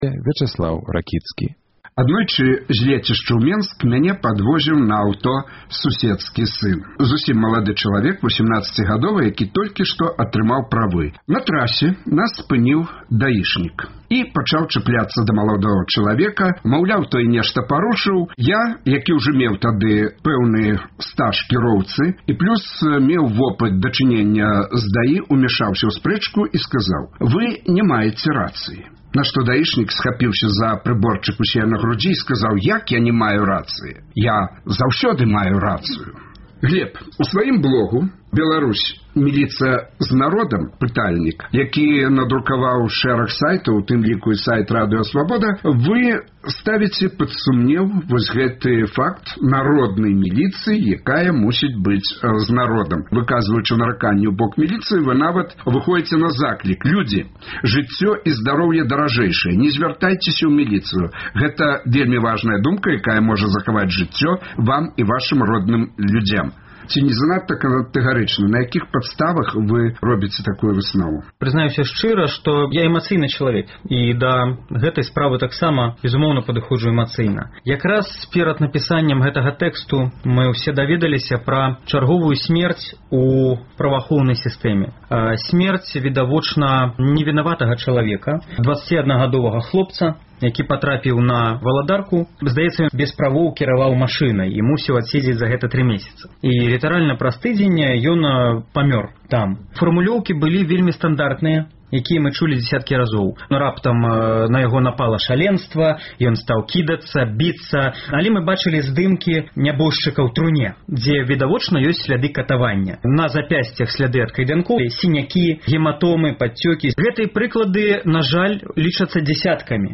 Але ці заўсёды яна абараняе пацярпелага і ці заўсёды яе дзеяньні адэкватныя адносна парушальніка? У дыскусіі